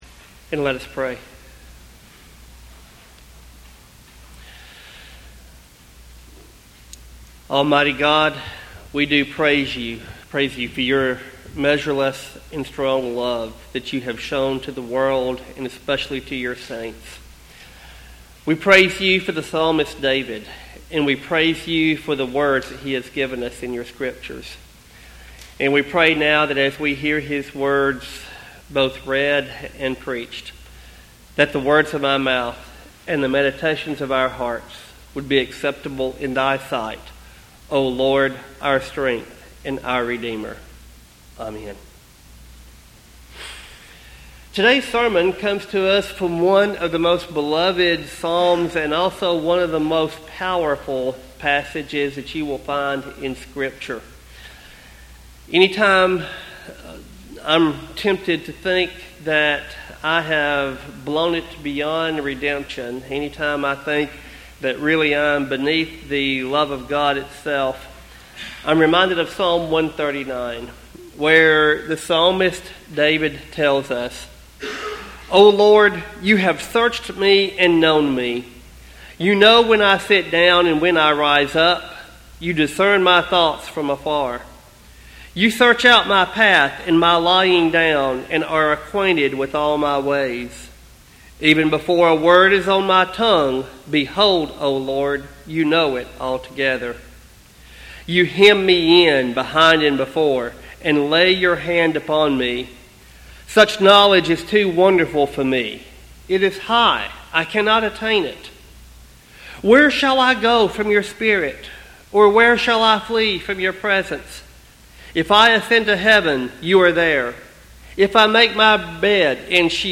Sermon text: Psalm 139.